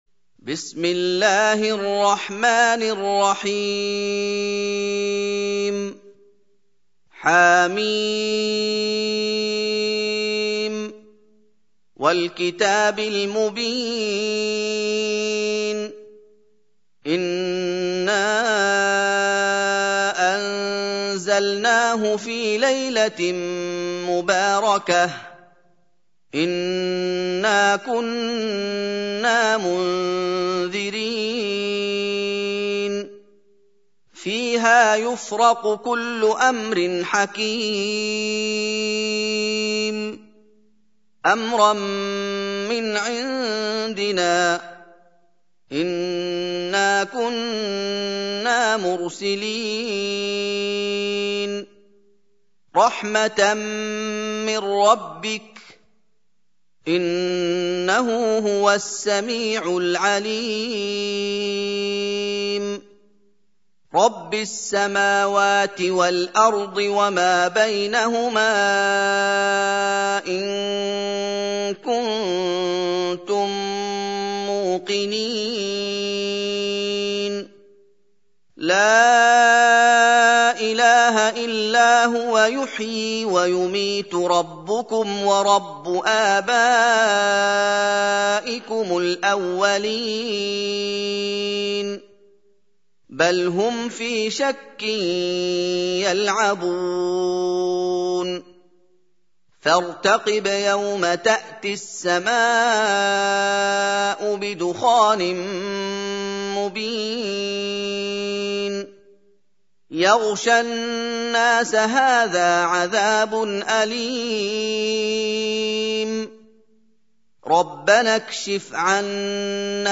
سورة الدخان | القارئ محمد أيوب